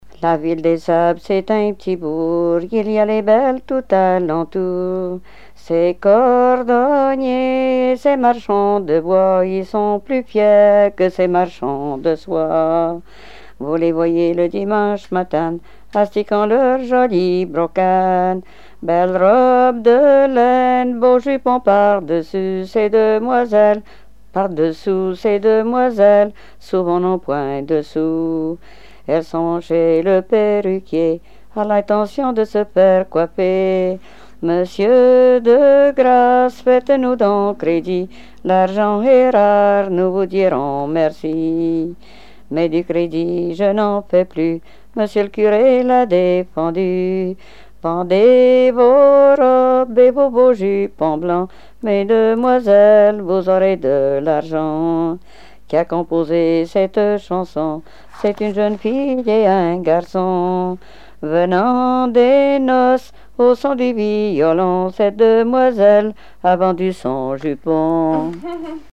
Genre énumérative
Répertoire de chansons traditionnelles et populaires